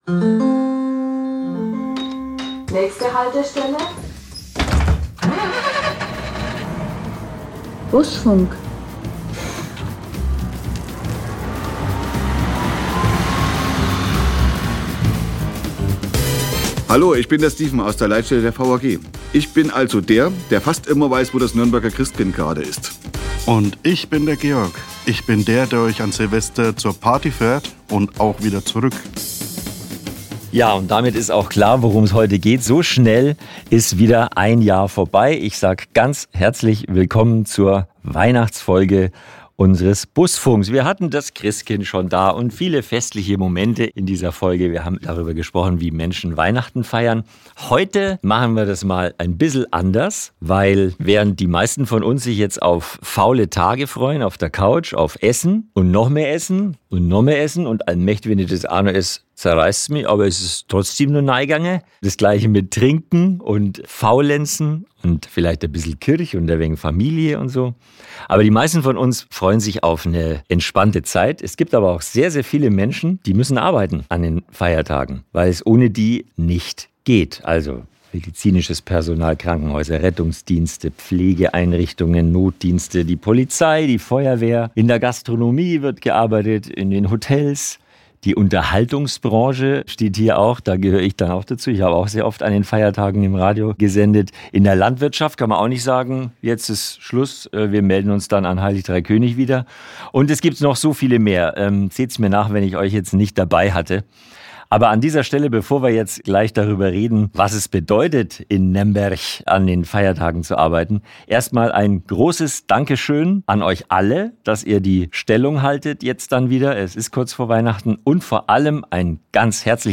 Was sie dort in den vergangenen Jahren erlebt haben und worauf sie sich freuen, erzählen sie in dieser festlichen Busfunk-Folge.